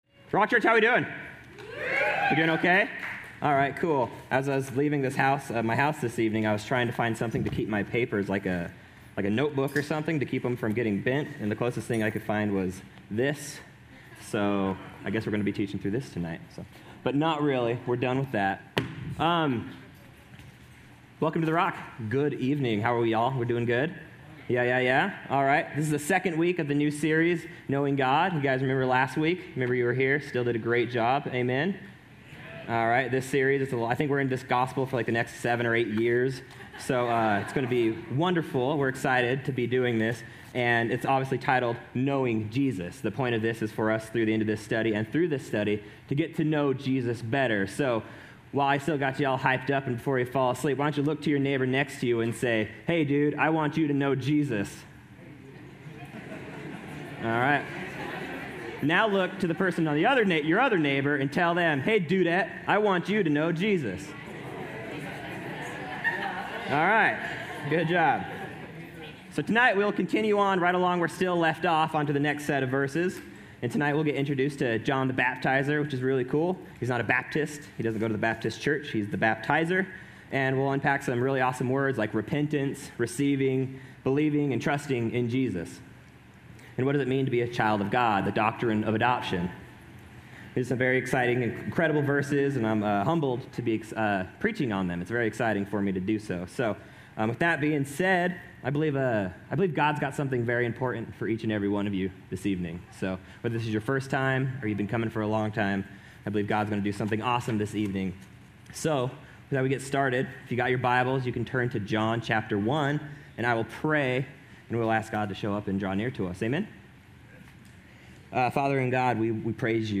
A message from the series "Your Love Remains."